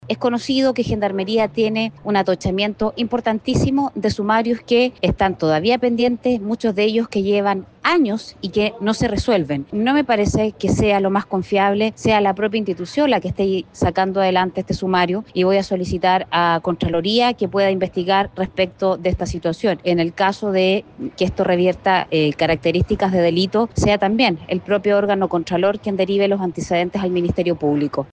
La diputada y presidenta de la Comisión de Defensa de la Cámara Baja, Camila Flores, adelantó que solicitará que la investigación sea asumida por un organismo externo.